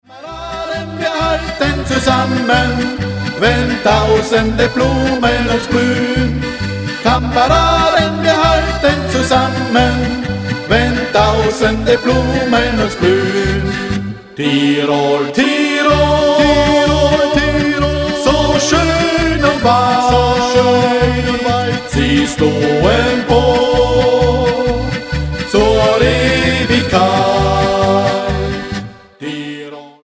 Genre: Volkstümliche Musik
Akkordeon, Gesang
Gitarre, Gesang
Kontrabass, Gesang